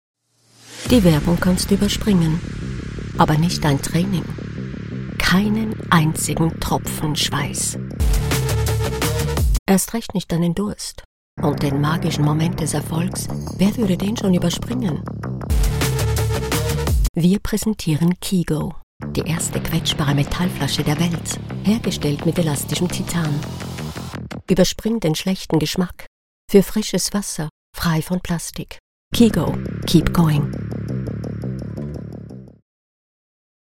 German (Switzerland)
Sensual Friendly Warm Get my Quote Add to quote Invited Starting at $150